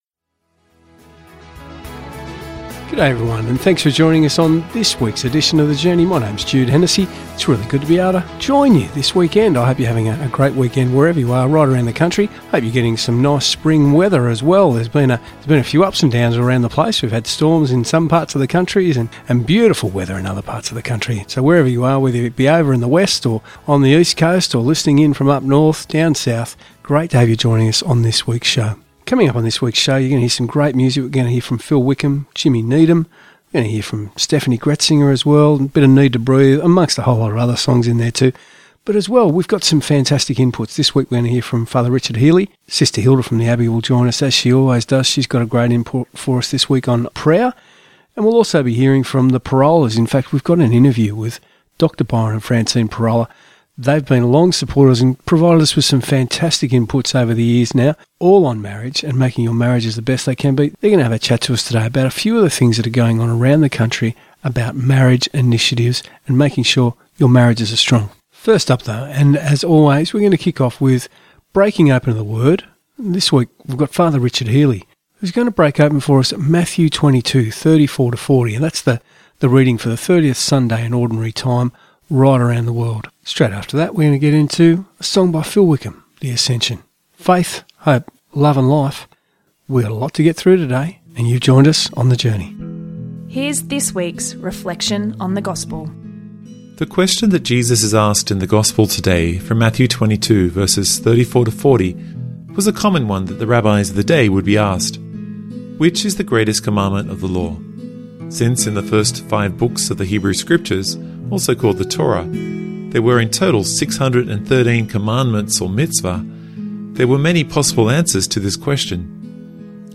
"The Journey" is a weekly Christian Radio program produced by the Catholic Diocese of Wollongong and aired on Christian Radio Stations around the country.
There are also regular interviews highlighting interesting things being done by people in the Church.